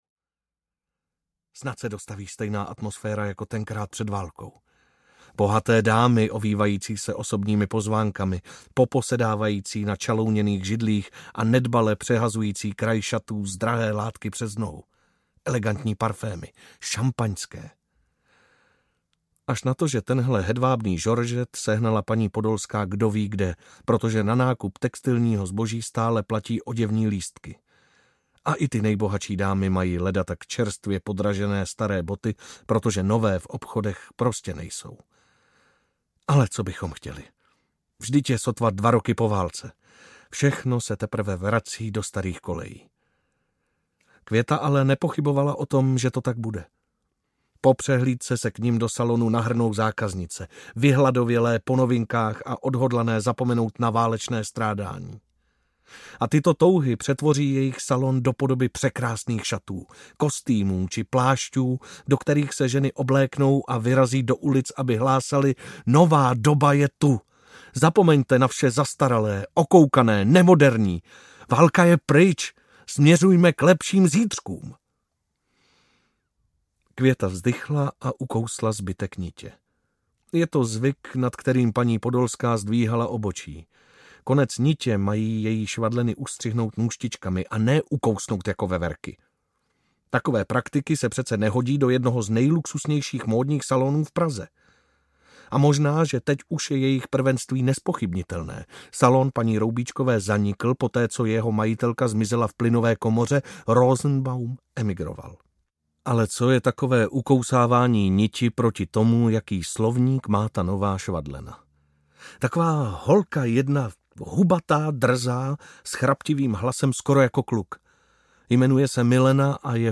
Kdo šije u Podolské audiokniha
Ukázka z knihy